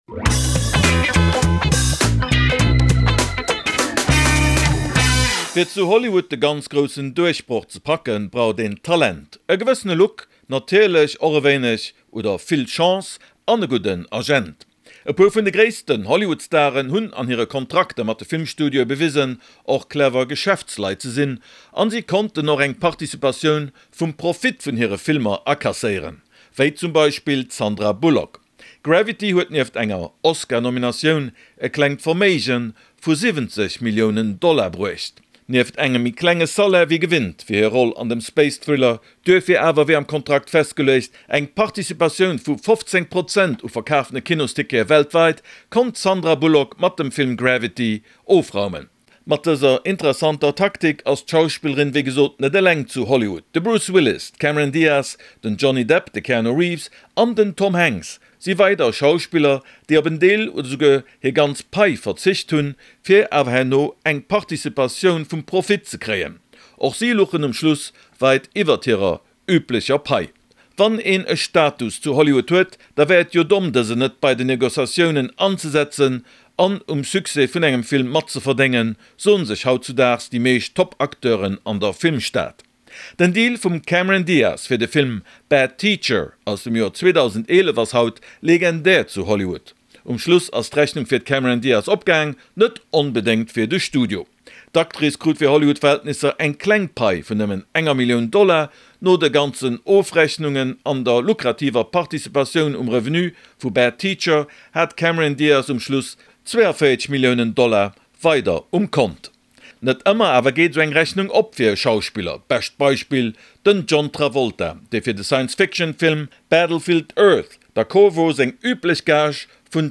D'Aktualitéit aus der Dramwelt, kommentéiert vum Lokal Korrespondent